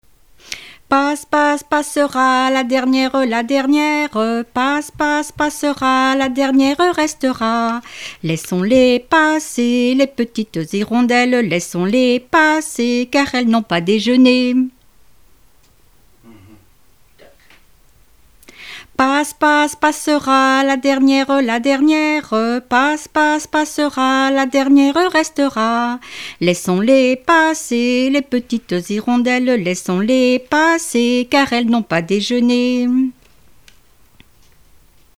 Enfantines - rondes et jeux
Répertoire de chansons populaires et traditionnelles
Pièce musicale inédite